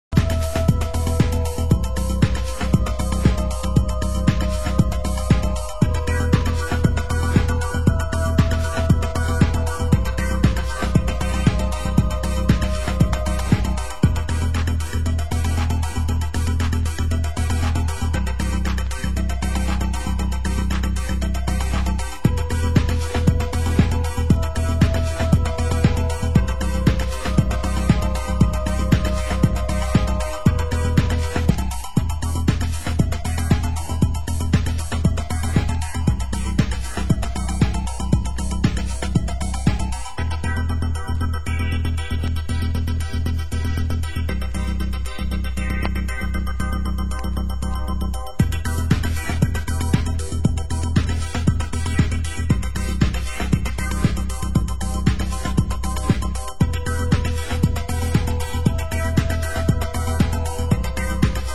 Genre: Techno
Genre: Tech House